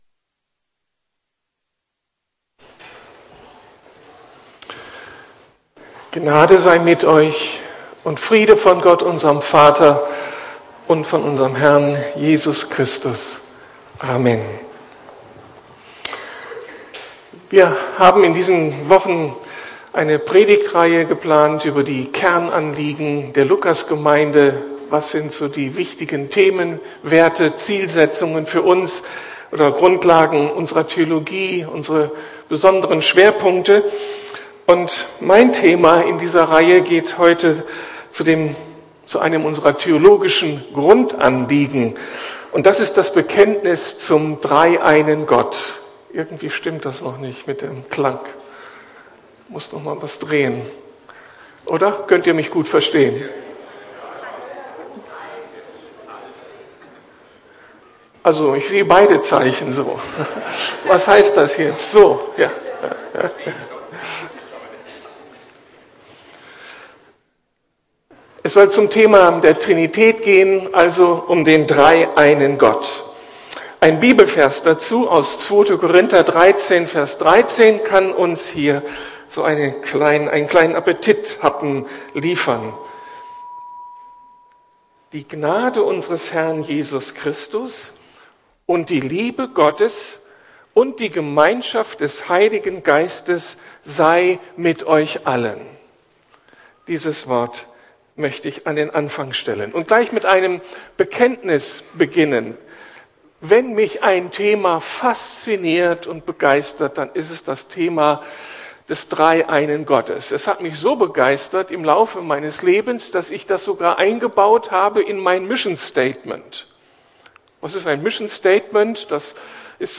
Predigten der LUKAS GEMEINDE